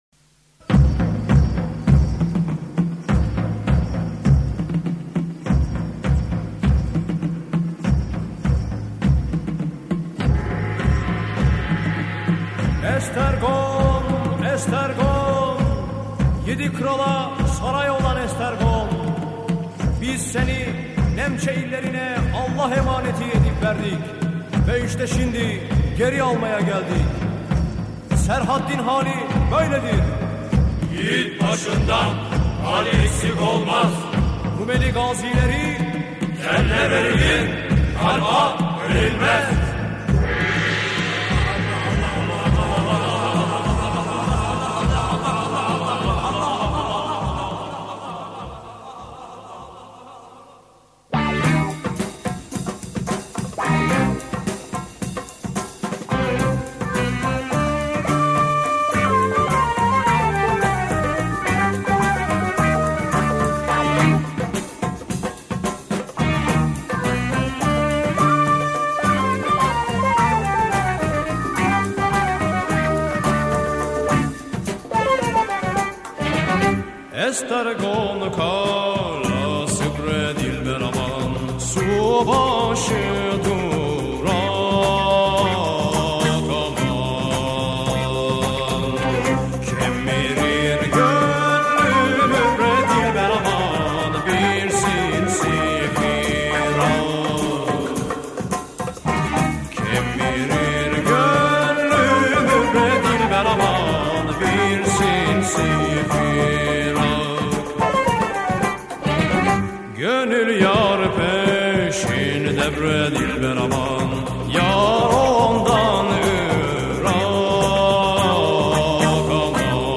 Estergon Kalesi címmel egy hajdanán janicsárindulóként használt, de ma is népszerű virágének született az erődítményről........"